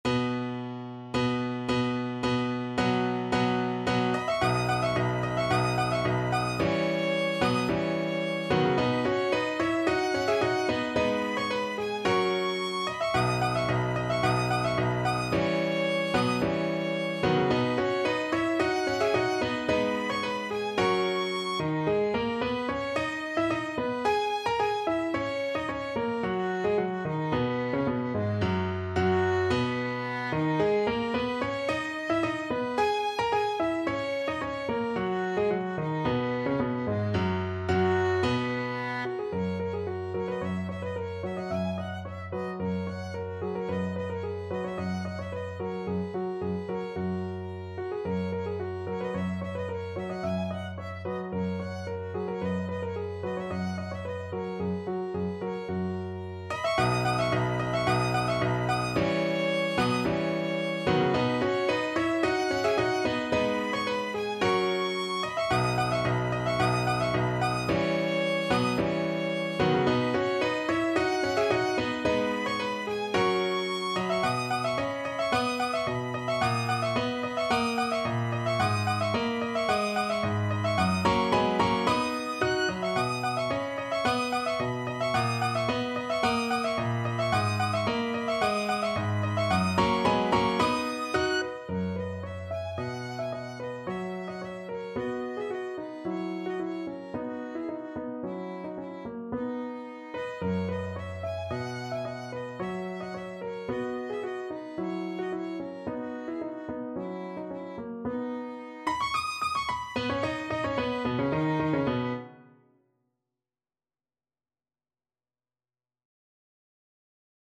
Classical Mendelssohn, Felix Bergamask from A Midsummer Night's Dream (A Dance of Clowns) Violin version
Violin
B major (Sounding Pitch) (View more B major Music for Violin )
= 110 Allegro di molto (View more music marked Allegro)
2/2 (View more 2/2 Music)
Classical (View more Classical Violin Music)